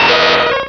sovereignx/sound/direct_sound_samples/cries/muk.aif at master